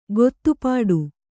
♪ gottupāḍu